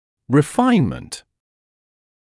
[rɪ’faɪnmənt][ри’файнмэнт](об элайнерах)дополнительная корректирующая капа для доведения результата лечения до запланированного после завершения основной части лечения (обычно мн.ч. refinements)